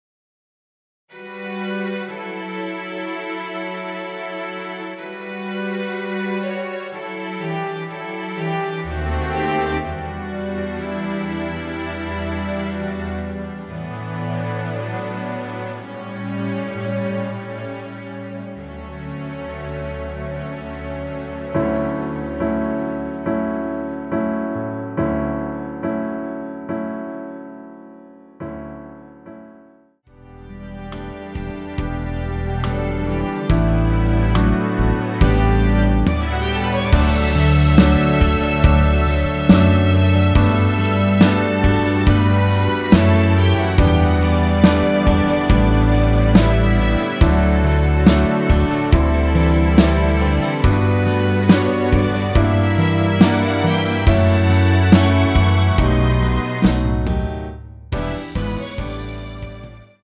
원키에서(-1)내린 MR입니다.
◈ 곡명 옆 (-1)은 반음 내림, (+1)은 반음 올림 입니다.
앞부분30초, 뒷부분30초씩 편집해서 올려 드리고 있습니다.
중간에 음이 끈어지고 다시 나오는 이유는